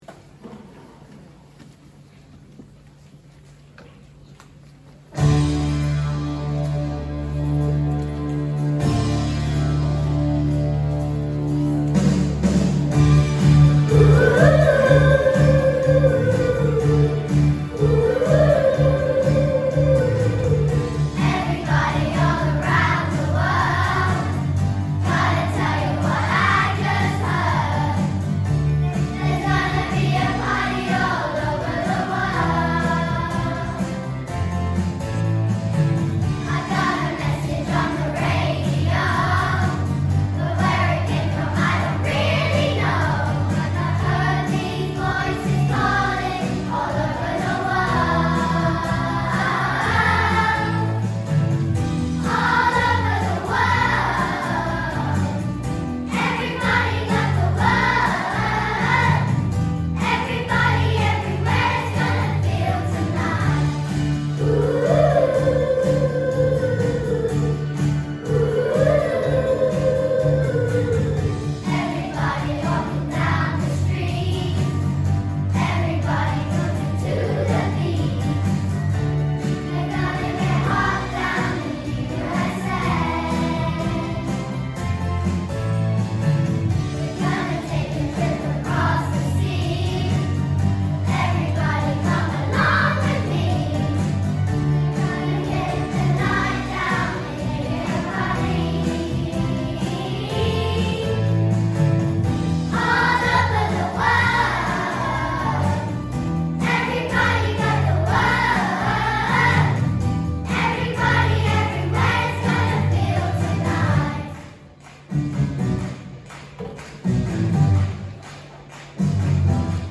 All Over the World | Y4/5/6 Choir